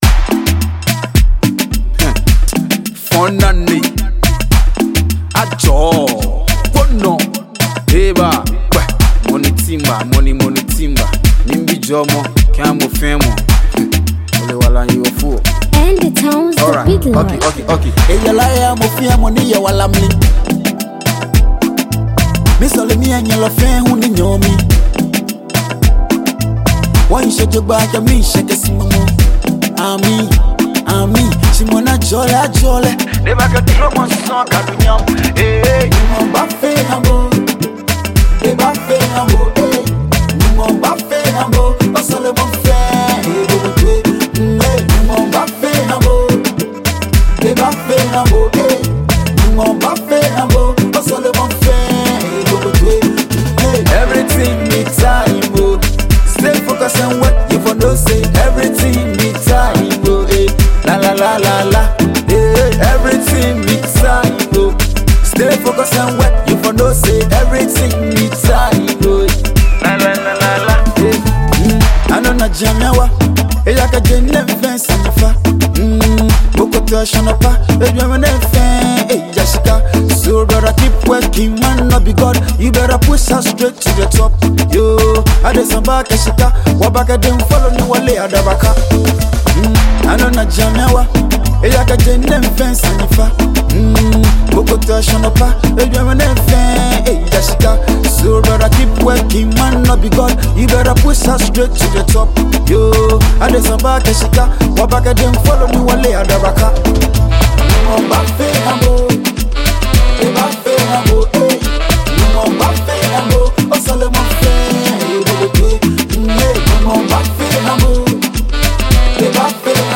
Budding Ga rapper